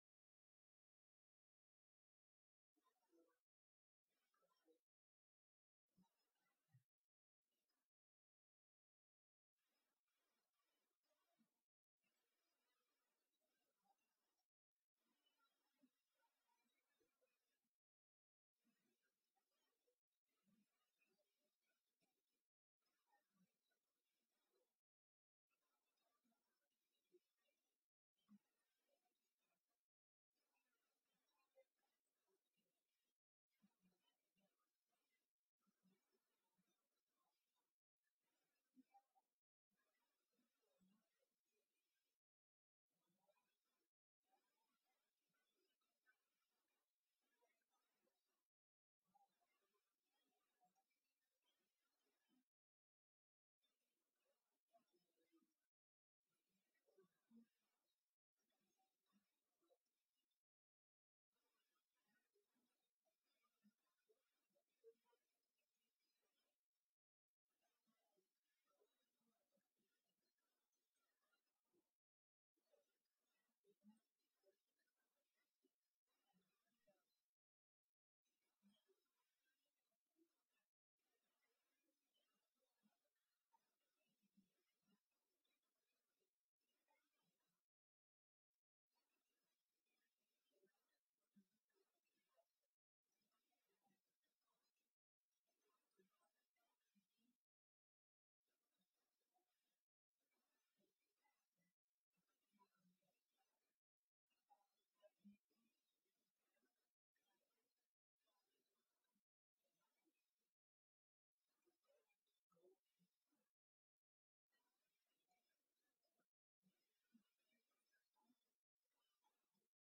Аудиокнига Между нами | Библиотека аудиокниг